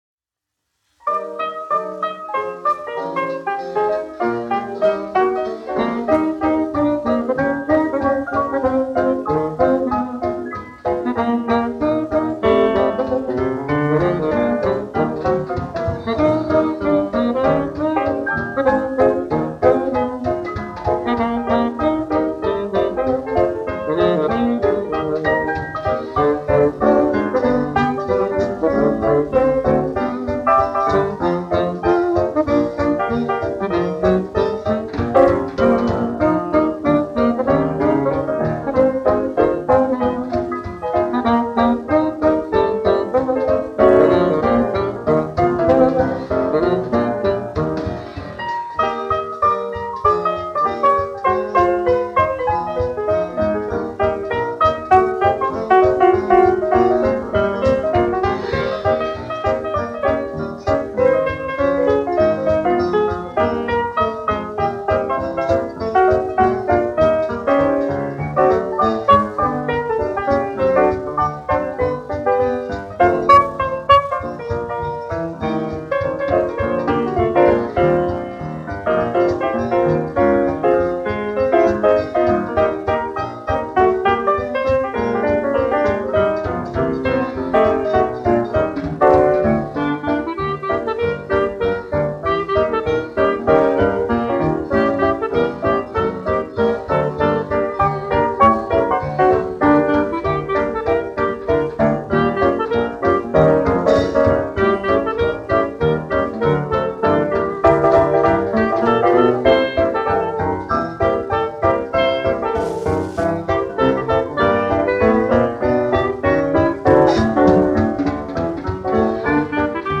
1 skpl. : analogs, 78 apgr/min, mono ; 25 cm
Populārā instrumentālā mūzika
Fokstroti
Latvijas vēsturiskie šellaka skaņuplašu ieraksti (Kolekcija)